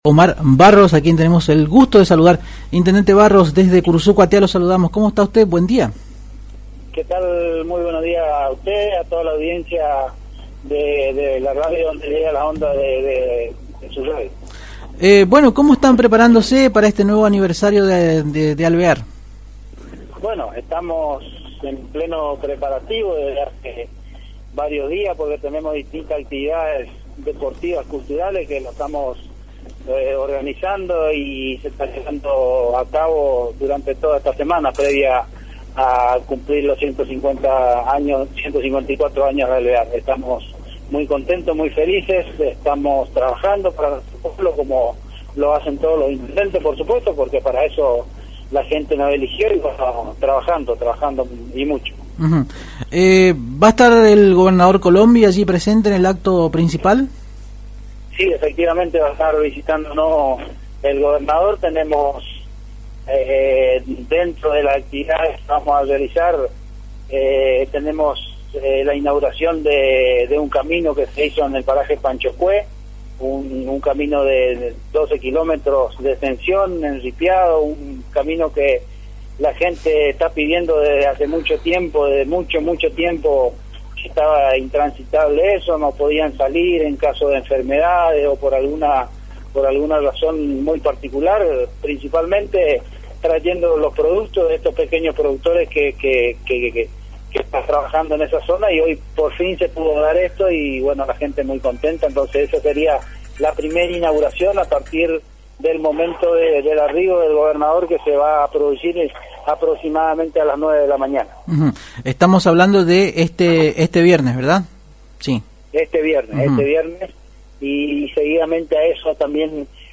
(Audio) En contacto con Arriba Ciudad Omar Barros, Intendente de la localidad de Alvear contó algunos detalles de los preparativos que se están realizando en éstos días para la celebración del 154º aniversario de Alvear que tendrá su epicentro éste viernes.
En diálogo con la AM 970 Radio Guarani indicó que desde hace un tiempo "estamos preparando y organizando distintas actividades deportivas y culturales durante toda ésta semana previa al cumplimiento de los 154 años de Alvear".